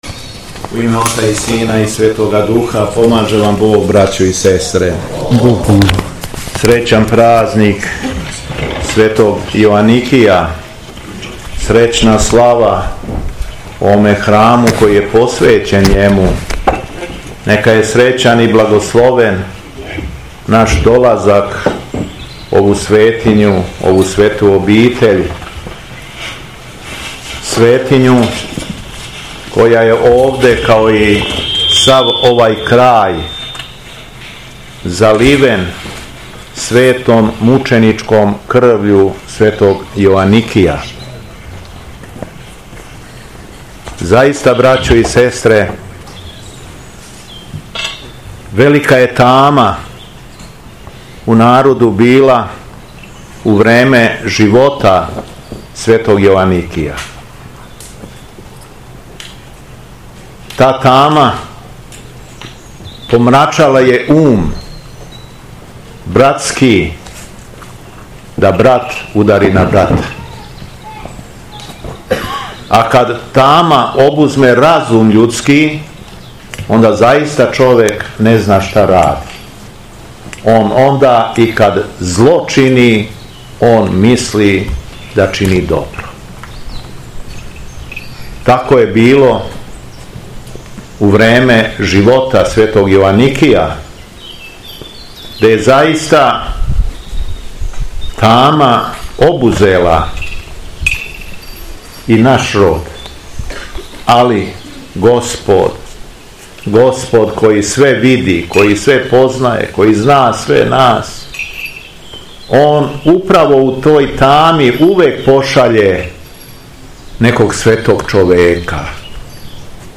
Његово Високопреосвештенство Митрополит шумадијски Јован служио је Свету Архијерејску Литургију у манастиру Венчац надомак Аранђеловца, поводом крсне...
Беседа Његовог Високопреосвештенства Митрополита шумадијског г. Јована